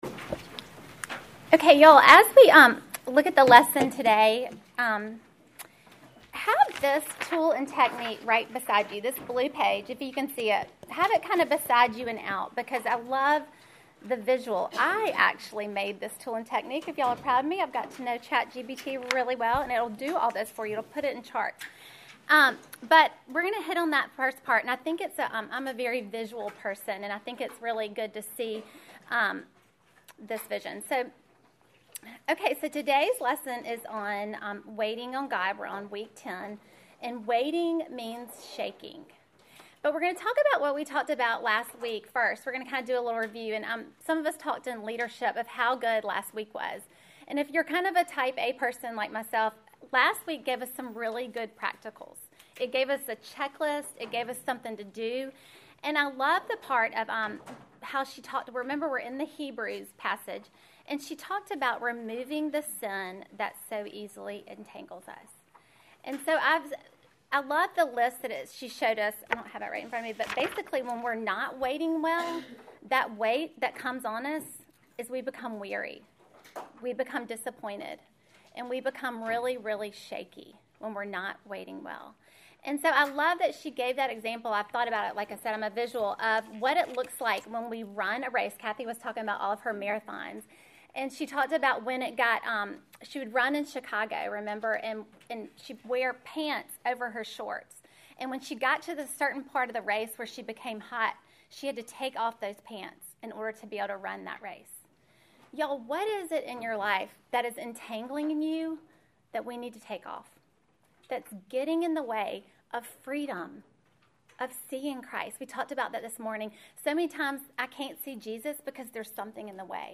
Welcome to the tenth lesson in our series WAITING ON GOD!